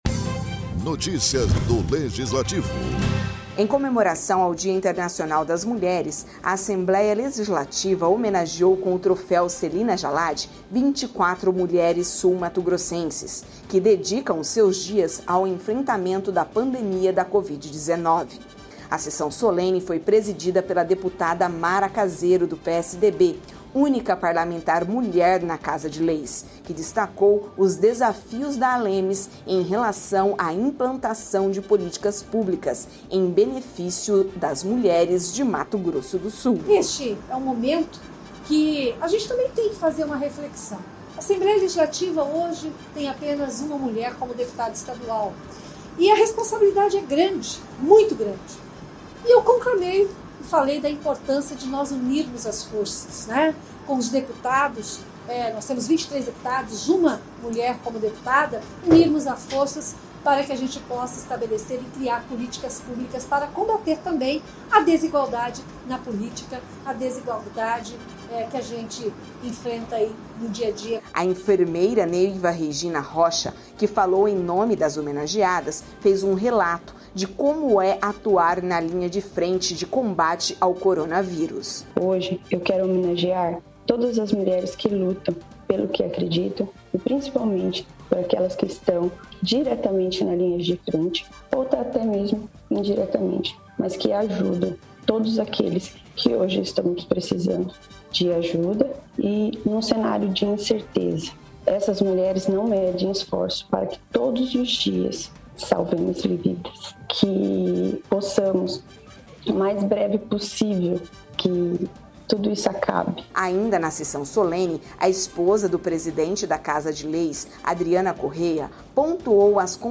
Em comemoração ao Dia Internacional das Mulheres, a Assembleia Legislativa homenageou com o troféu Celina Jallad, 24 mulheres sul-mato-grossenses, que dedicam seus dias ao enfrentamento da pandemia da covid-19. A sessão solene foi presidida pela deputada Mara Caseiro (PSDB), única parlamentar mulher na Casa de Leis.